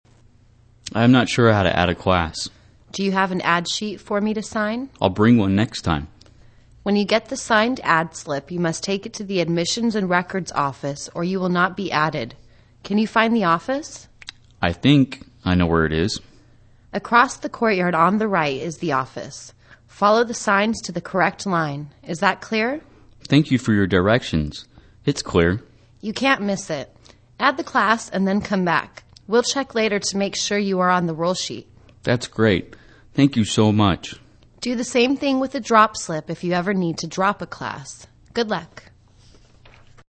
在线英语听力室大学生活英语-Adding a Class(3)的听力文件下载,英语情景对话-大学生活-在线英语听力室